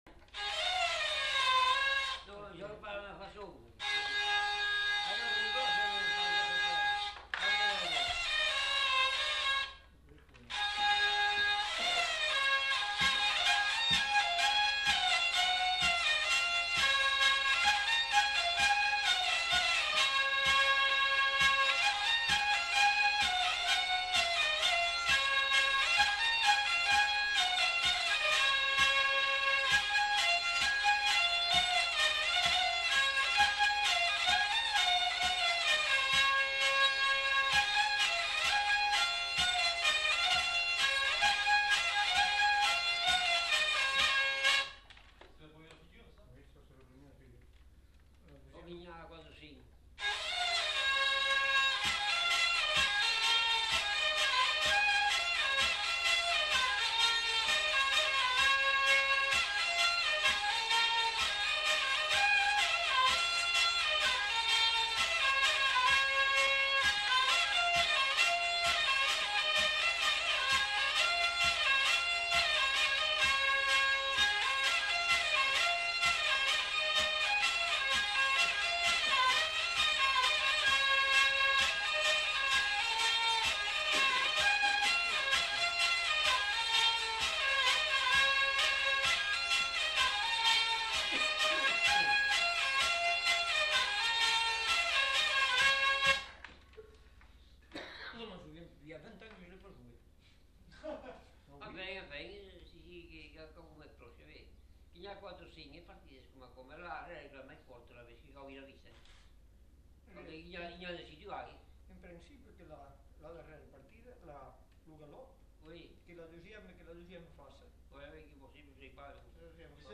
Lieu : Vielle-Soubiran
Genre : morceau instrumental
Instrument de musique : vielle à roue
Danse : quadrille
En fin de séquence quelques commentaires sur le quadrille.